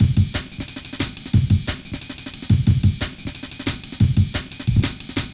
amenattempt1_2.wav